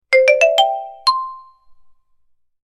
Xylofon Success Sound Effect
Description: Xylofon success sound effect. Enhance your mobile apps and games with this short, positive xylophone melody.
Xylofon-success-sound-effect.mp3